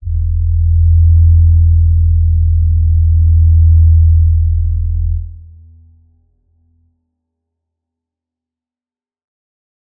G_Crystal-E2-pp.wav